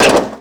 car_light_3.wav